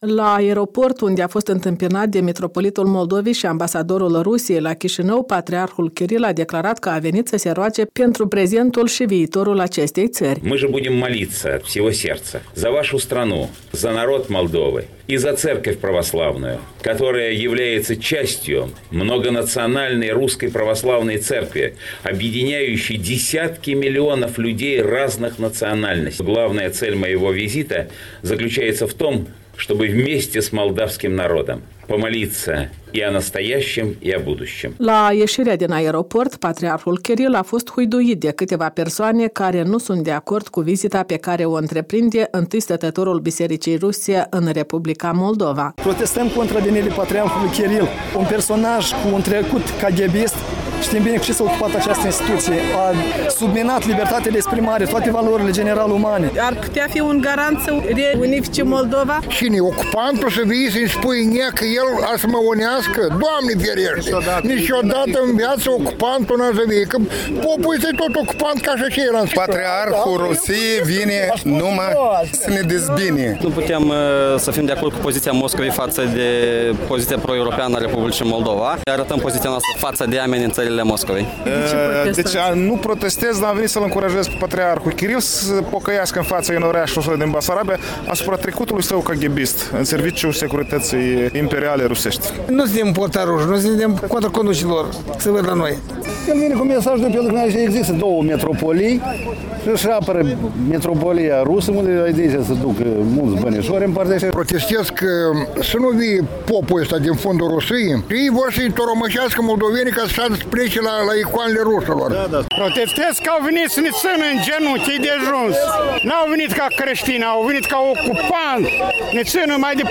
Un reportaj de la protestele prilejuite de sosirea Patriarhului Moscovei la Chișinău